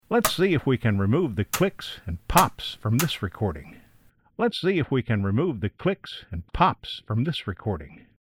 Press ESC to close.Sometimes an audio track will have pops and clicks. These sounds are often high-energy annoyances that span a wide range of frequencies.
MONO-Audio-Clicks-Pops-COMBO.mp3